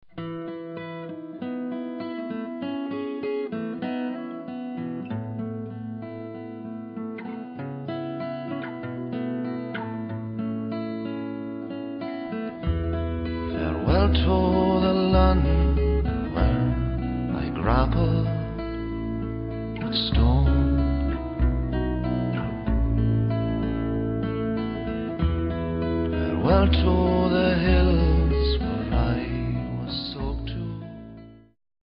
is a slow, easy song